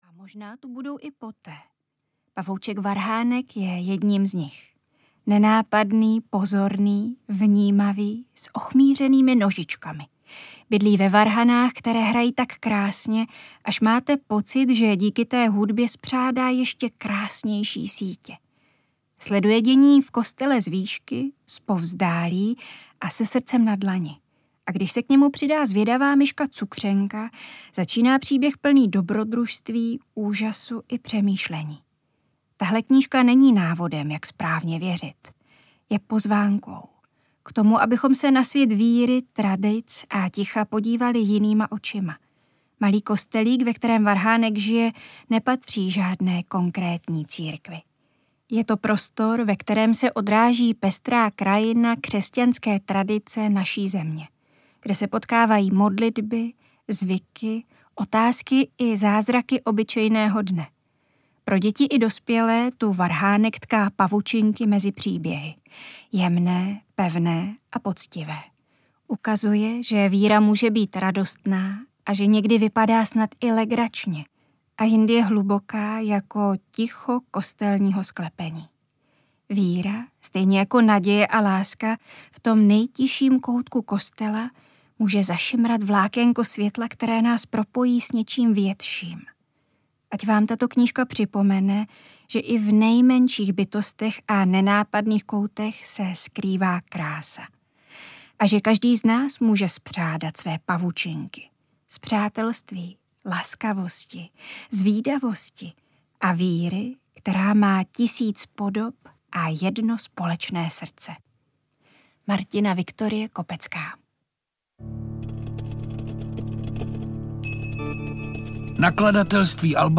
Pavouček Varhánek audiokniha
Ukázka z knihy
• InterpretPetr Čtvrtníček